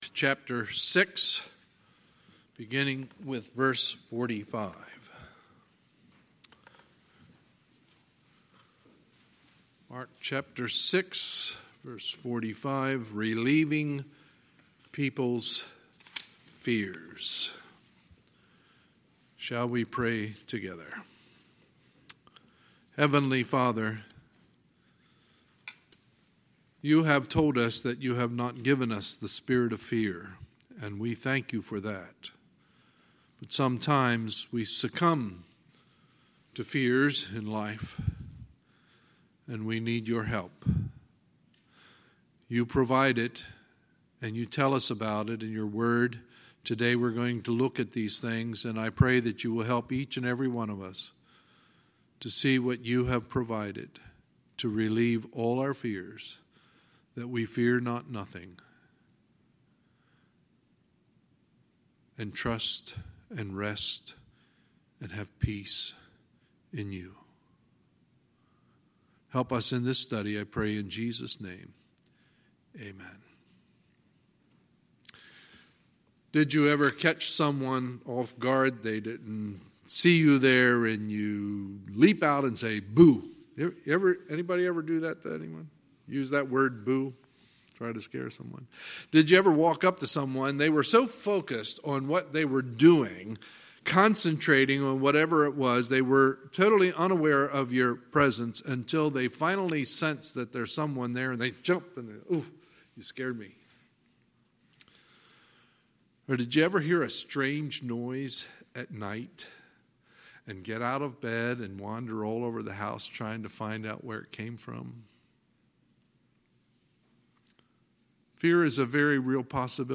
Sunday, June 23, 2019 – Sunday Morning Service